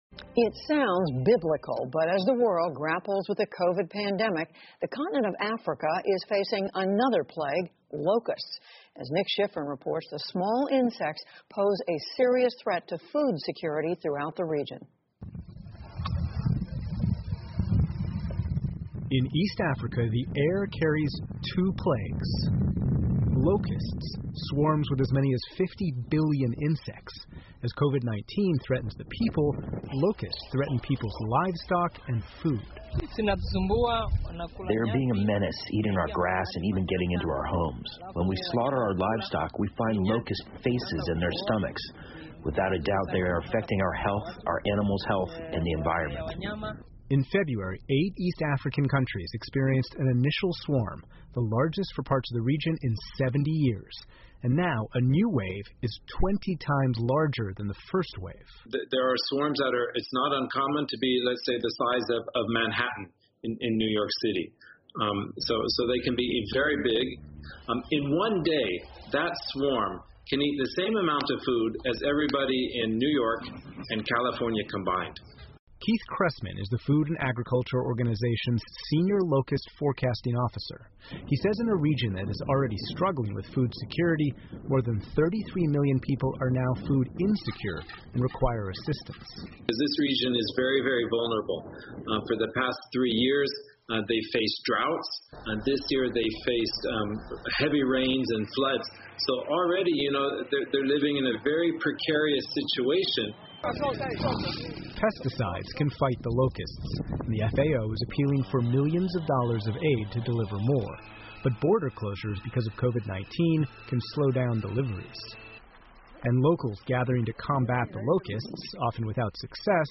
PBS高端访谈: 听力文件下载—在线英语听力室